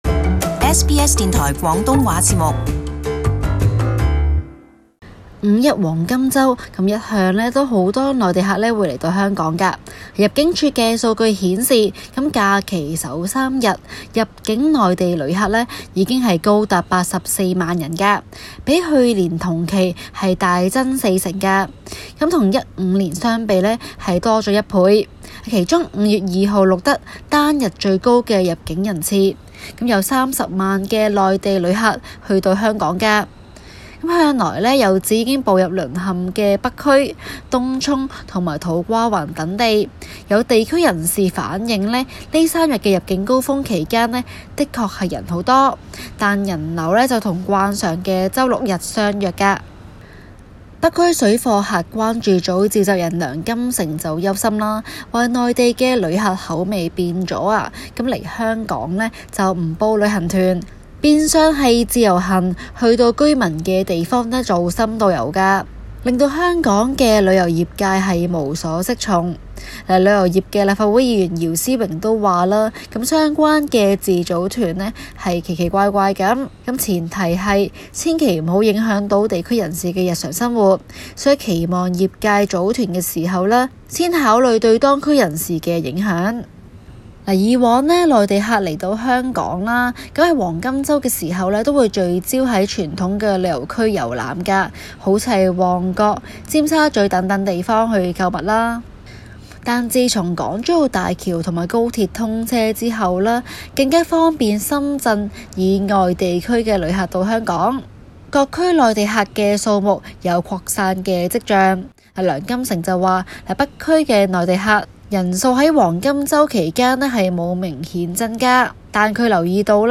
【中港快訊】